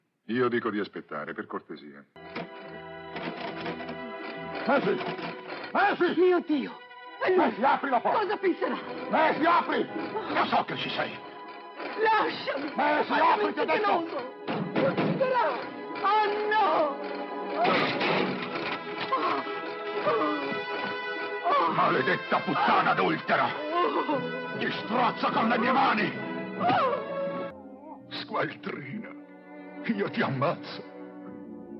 in cui doppia Jack Hawkins.